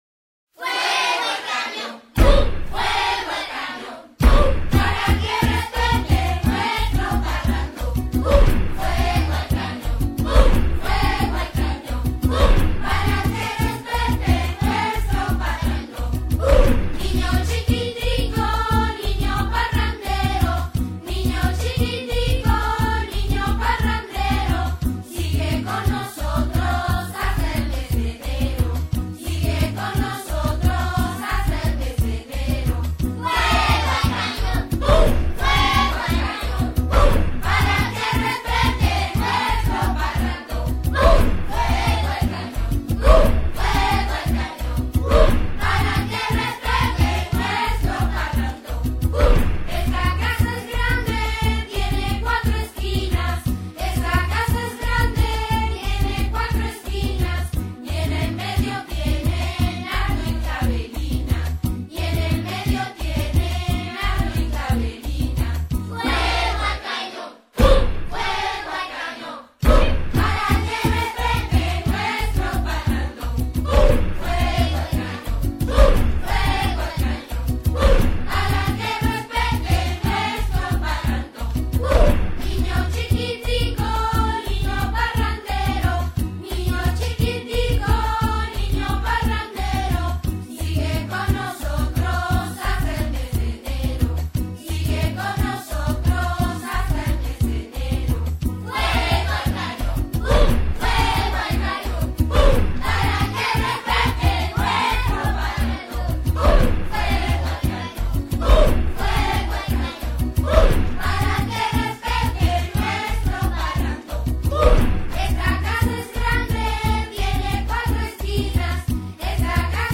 Volver a Podcast Ver todo Fuego al cañón on 2008-12-25 - Villancicos Descargar Otros archivos en esta entrada Nuestras Creencias Las creencias adventistas tienen el propósito de impregnar toda la vida.